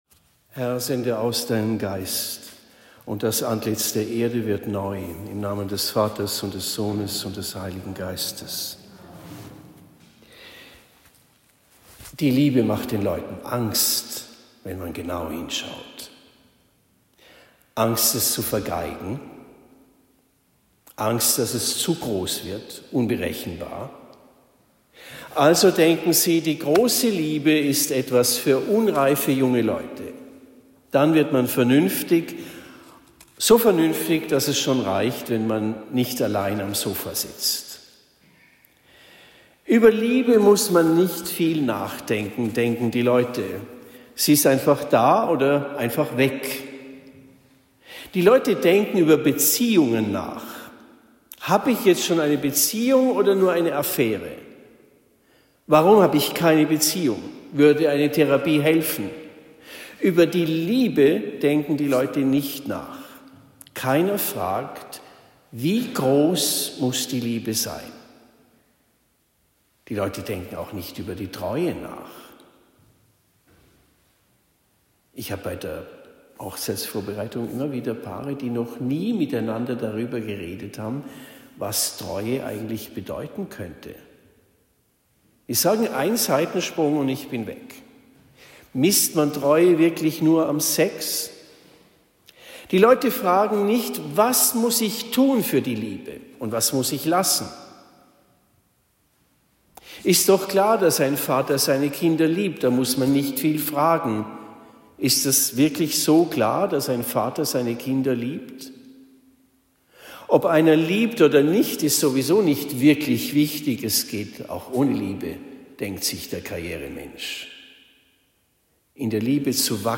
Predigt am 23. Mai 2025 in Hafenlohr